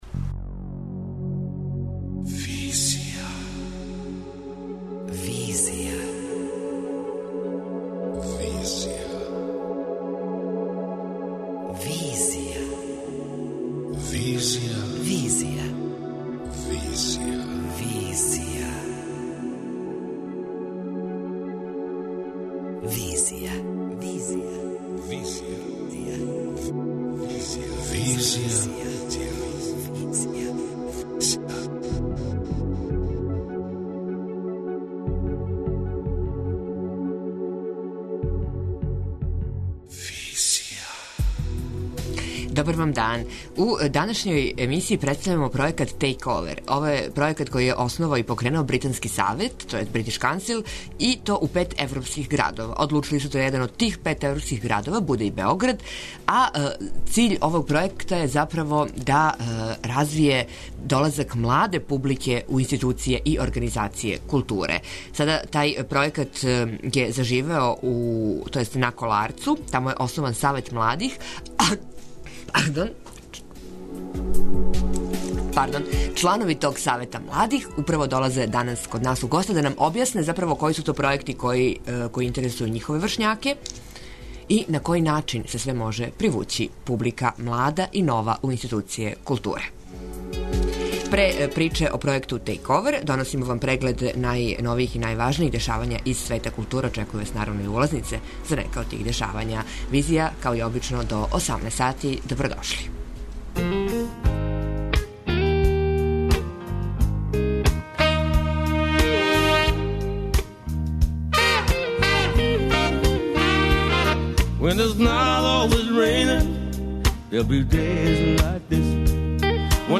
преузми : 26.73 MB Визија Autor: Београд 202 Социо-културолошки магазин, који прати савремене друштвене феномене.